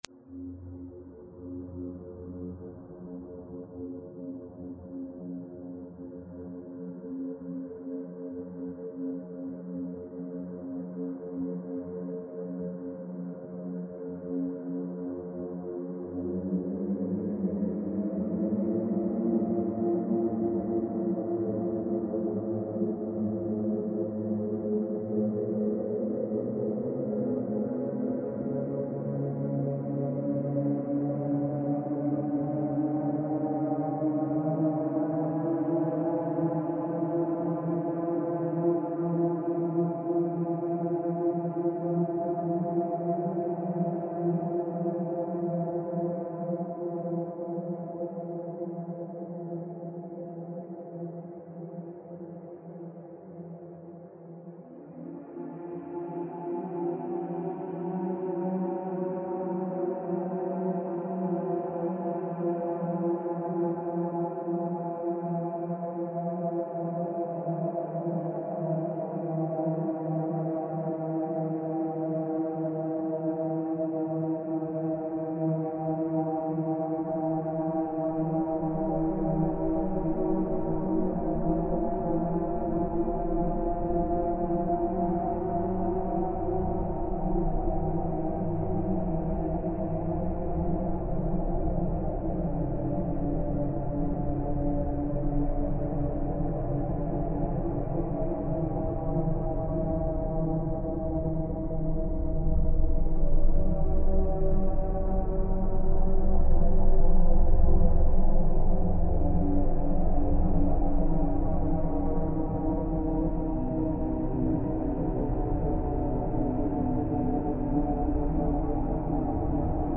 File under: Dark Ambient / Experimental